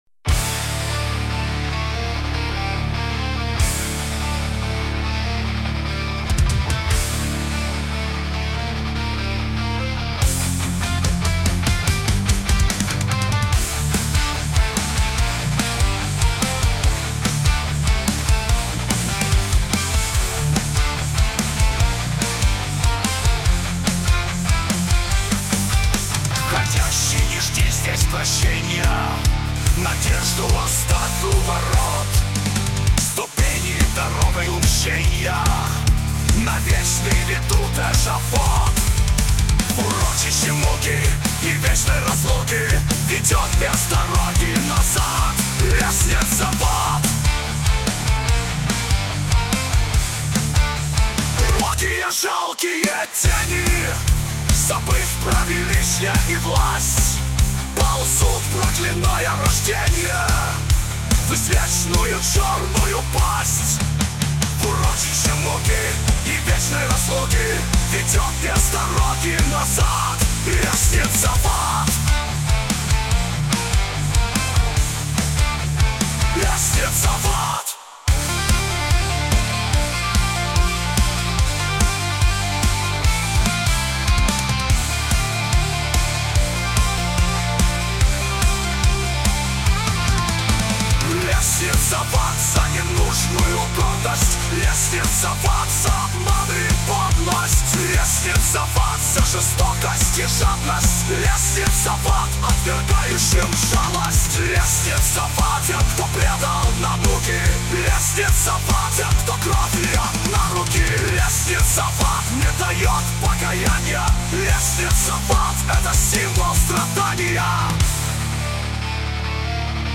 • Жанр: Металл